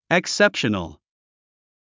発音 eksépʃənəl エクセプショナル